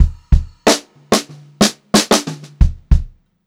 92ST2FILL1-L.wav